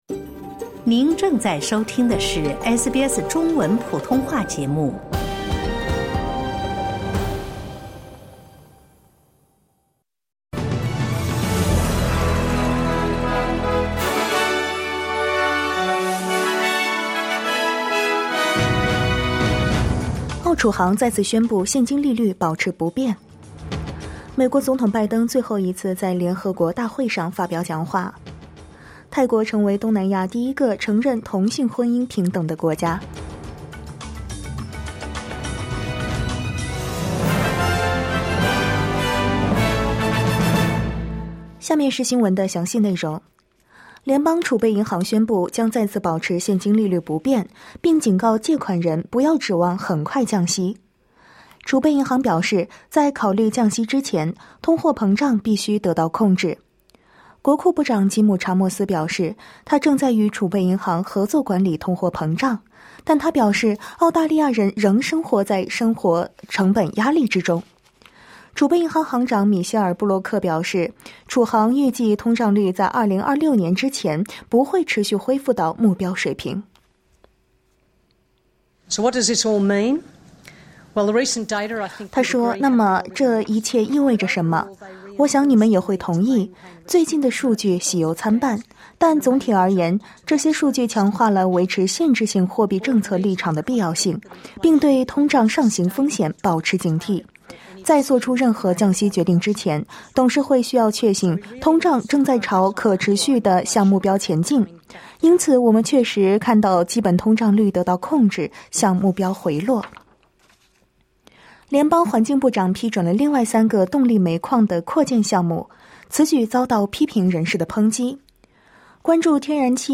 SBS早新闻（2024年9月25日）